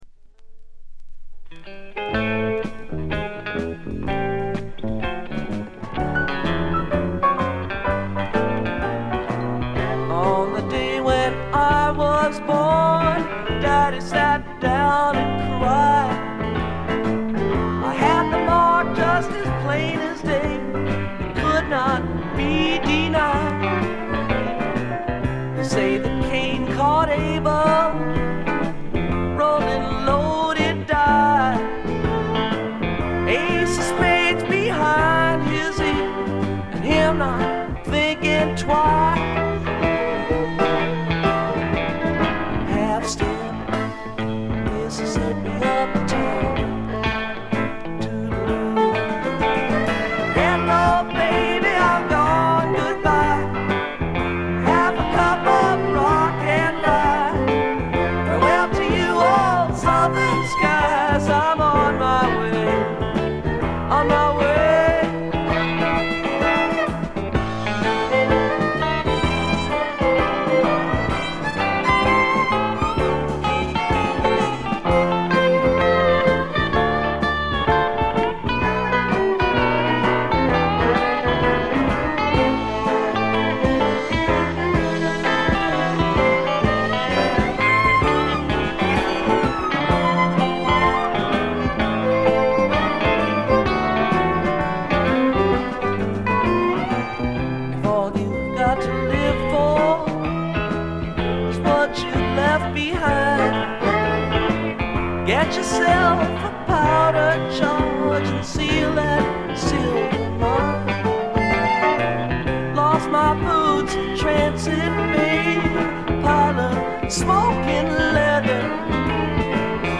静音部で軽微なバックグラウンドノイズが聴かれる程度。
試聴曲は現品からの取り込み音源です。